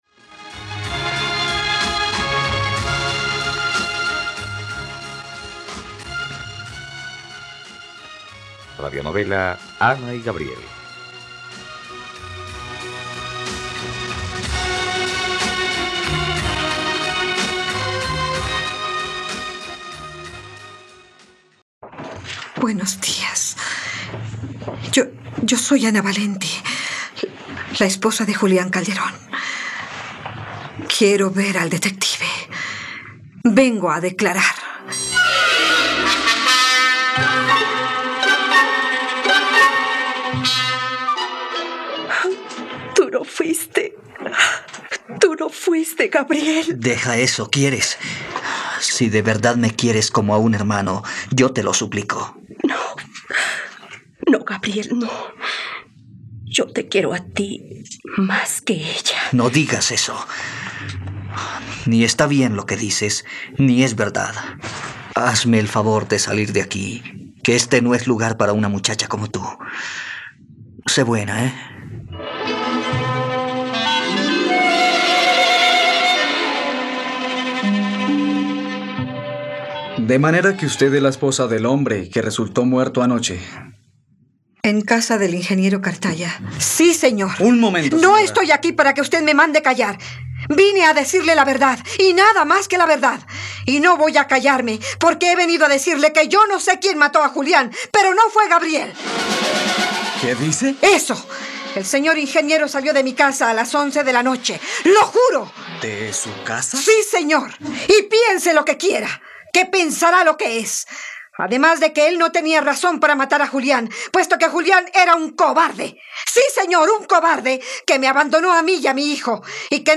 ..Radionovela. Escucha ahora el capítulo 95 de la historia de amor de Ana y Gabriel en la plataforma de streaming de los colombianos: RTVCPlay.